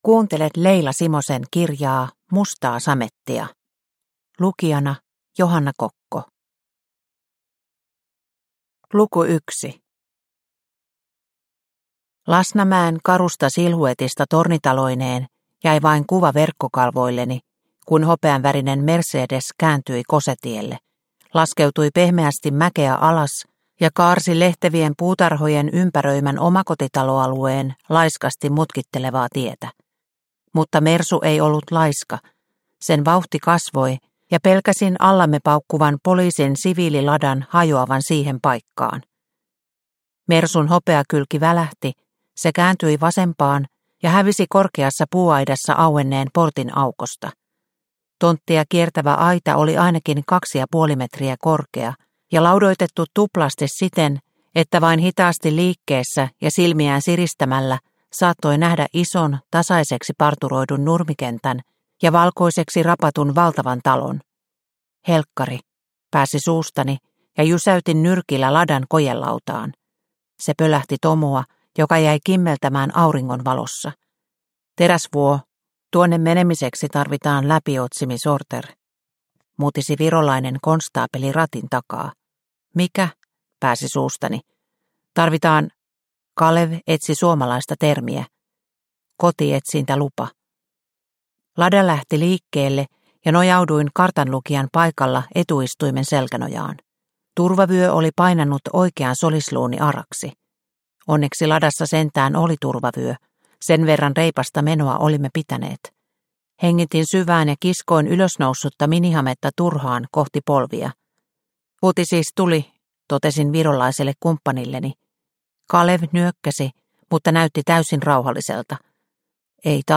Mustaa samettia – Ljudbok – Laddas ner